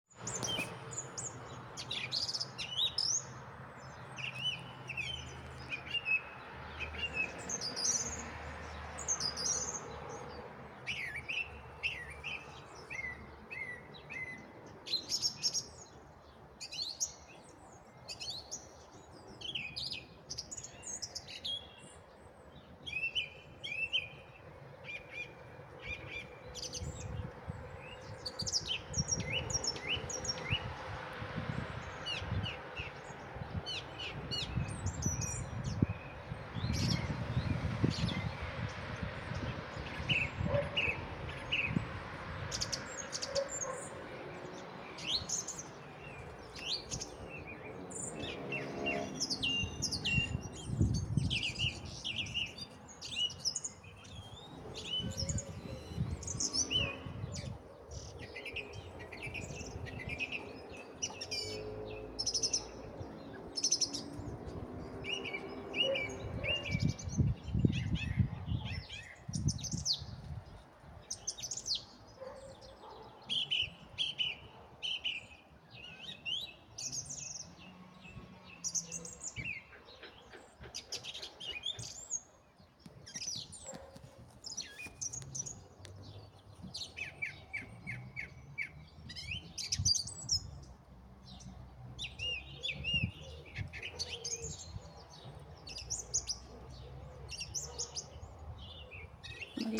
na zahradě slýchávám již týden následující ptačí zpěv, kteý je poměrně hlasitý a rozmanitý - viz nahrávka.
To je přeci Drozd zpěvný, to pozná každý...
Nechala jsem poslechnout celé a nahráce jsou tři ptáčci, Drozd, Střízlík obecný, a Zvonohlík zahradní, ale nejaktivněji zpívá právě drozd :)
neznamy-ptak-zpev.mp3